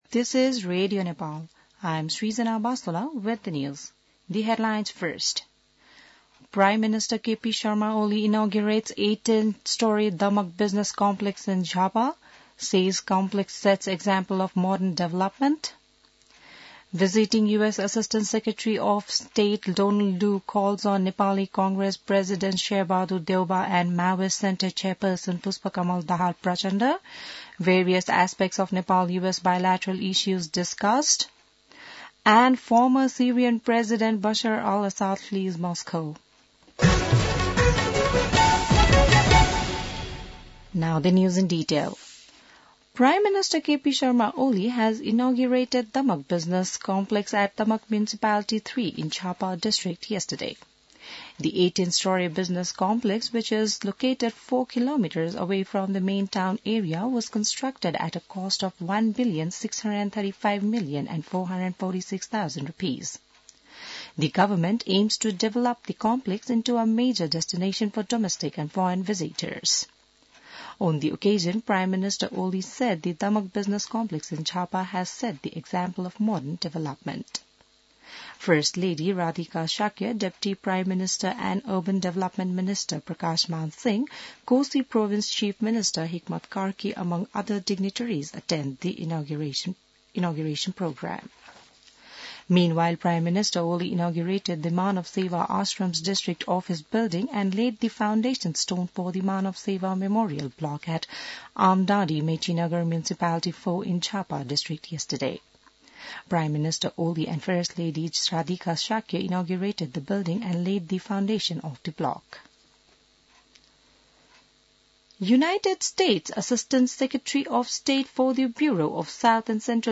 बिहान ८ बजेको अङ्ग्रेजी समाचार : २५ मंसिर , २०८१